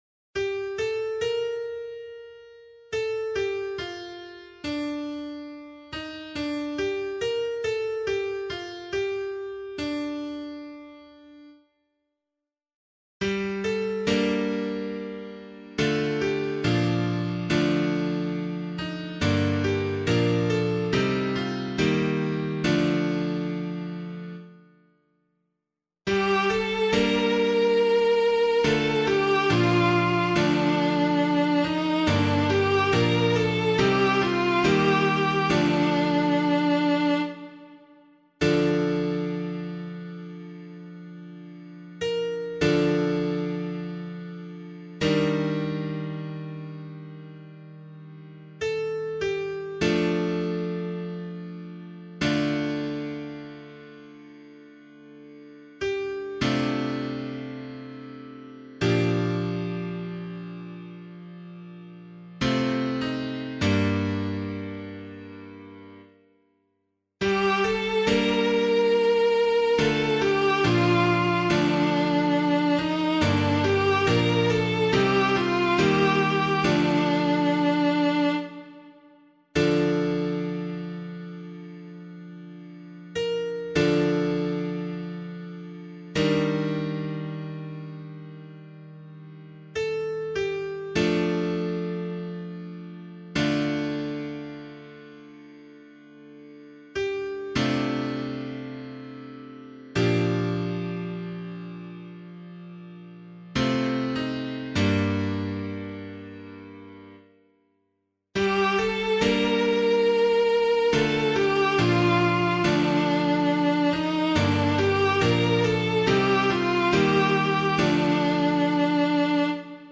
017 Lent 5 Psalm A [APC - LiturgyShare + Meinrad 3] - piano.mp3